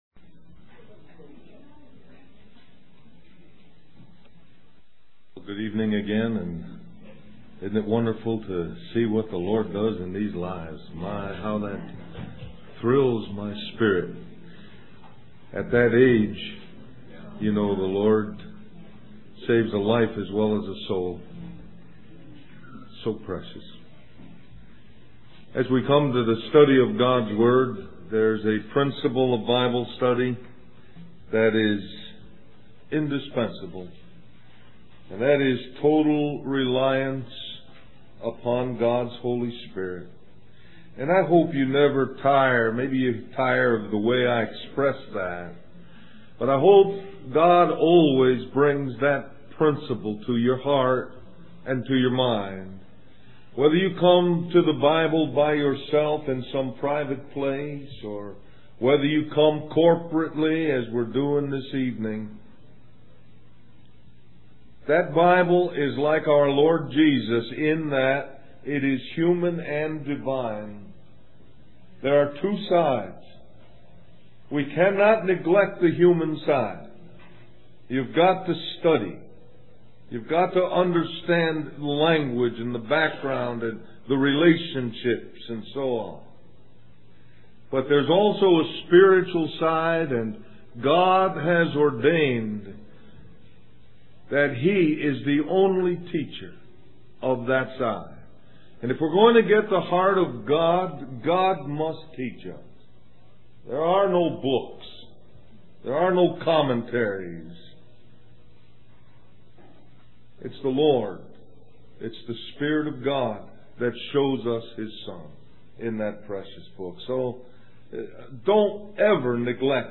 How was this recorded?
2001 Del-Mar-Va Labor Day Retreat Stream or download mp3 Summary After 3 days in the belly of the great fish